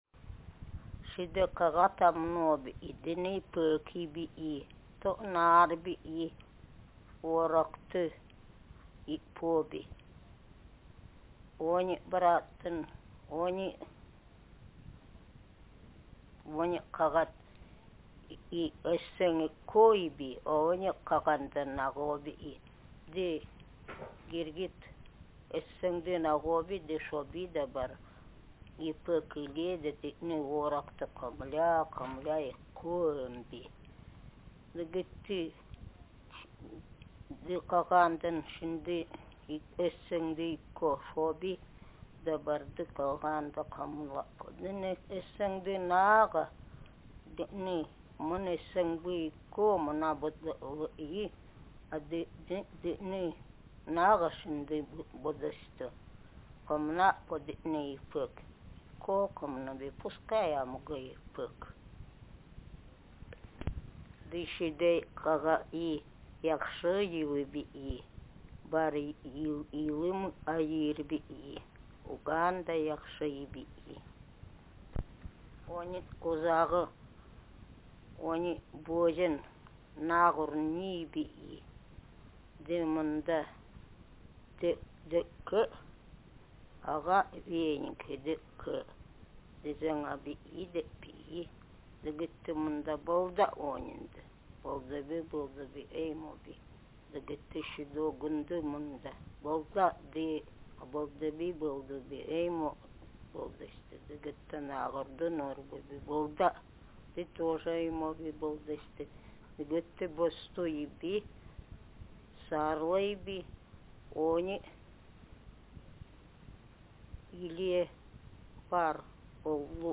Speaker sex f Text genre traditional narrative